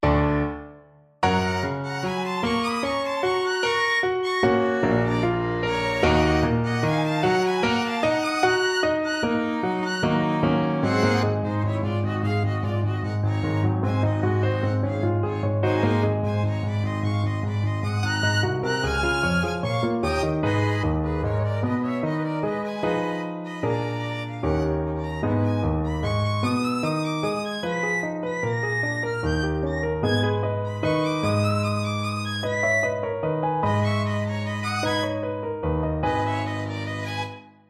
Chromatic and Enharmonic Modulations
MIDIWhite, Joseph, Violinesque, Op. 32, Allegro moderato, mm.90-120